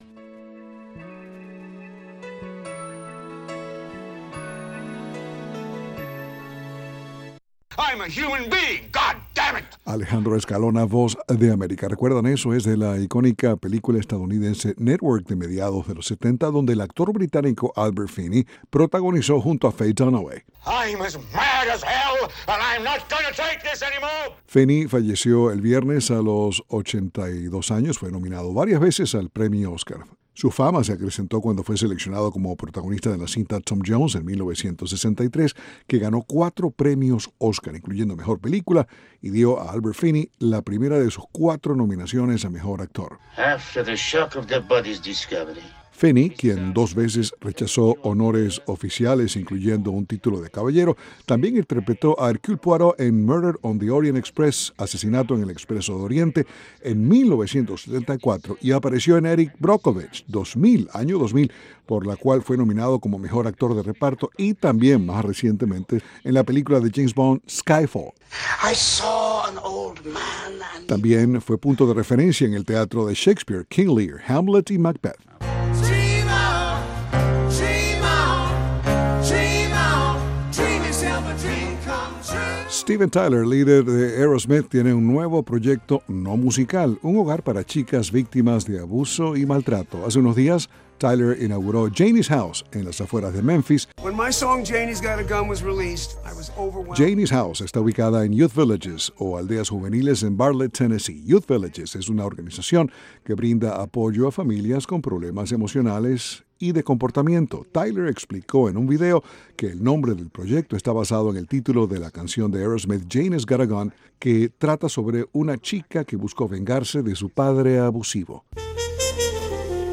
Noticias del entretenimiento - 10:30am